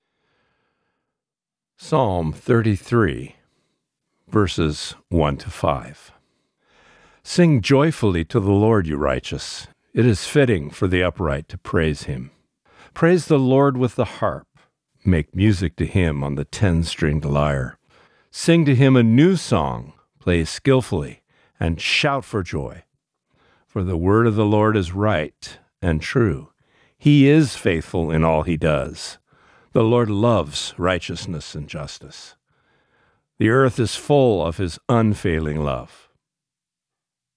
Reading: Psalm 33:1-5